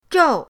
zhou4.mp3